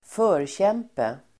Uttal: [²f'ö:rtjem:pe]